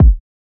MZ Kick [Zoom].wav